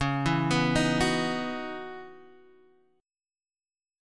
Dbm11 chord